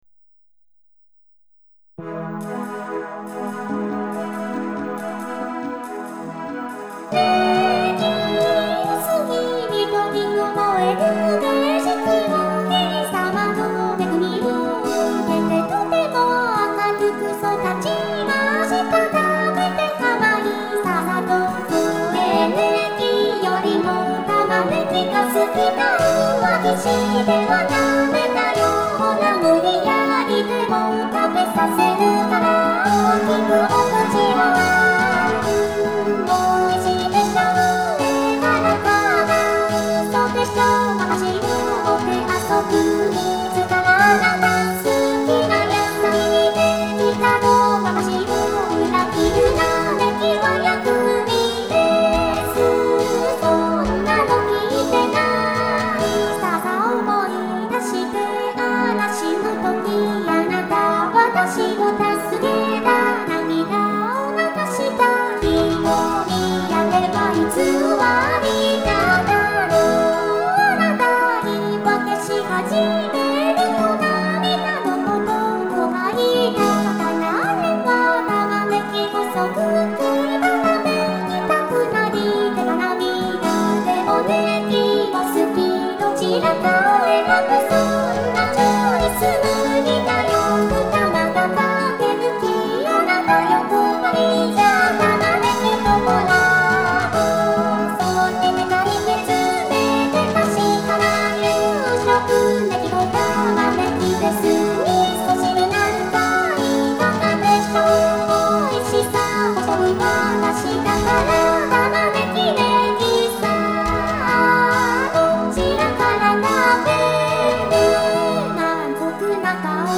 〜ボーカル版〜